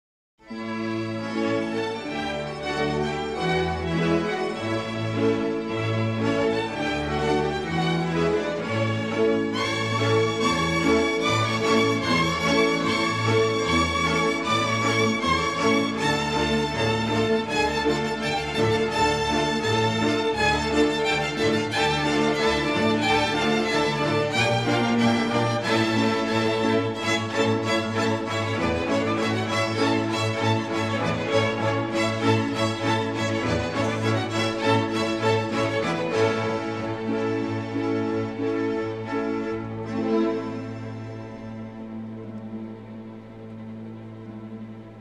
a simple, heartfelt theme for cimbalom and eerie
newly remastered from the best possible stereo sources